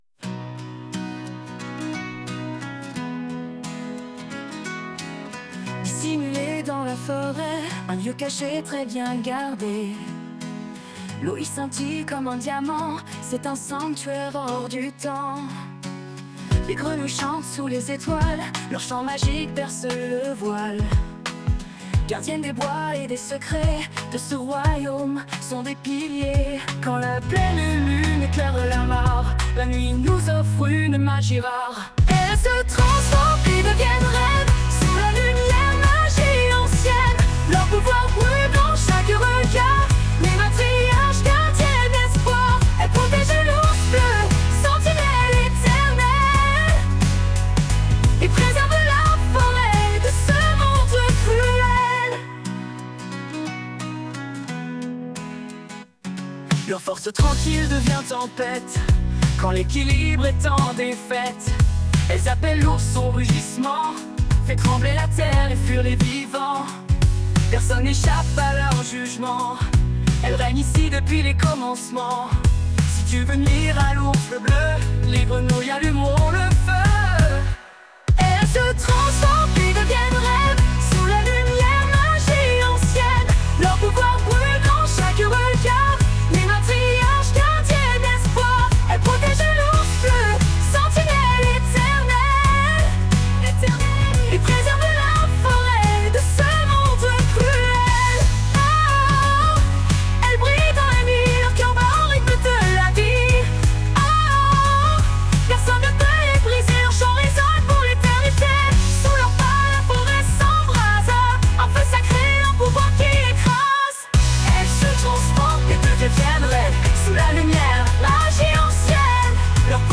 en ont également réalisé une reprise plus pop et édulcorée.